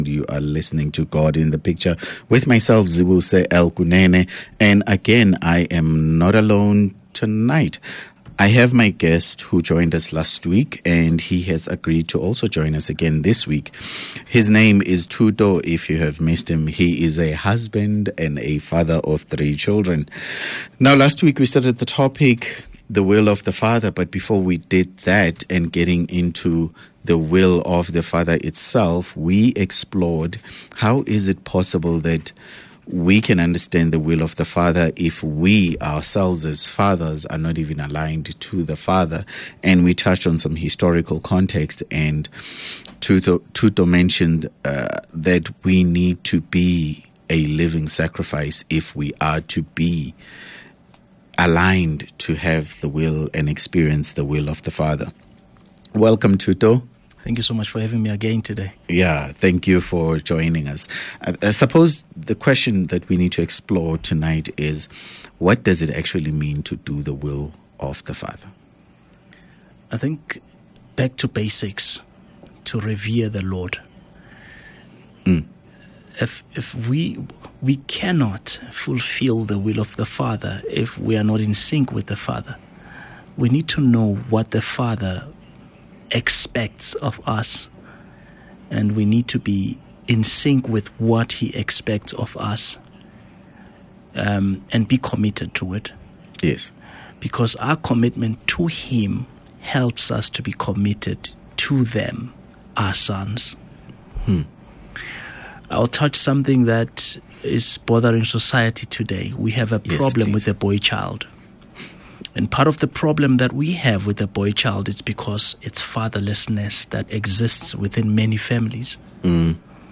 for an engaging and thought-provoking conversation. Don’t miss this inspiring continuation of a series that delves into stories of faith, family, and purpose.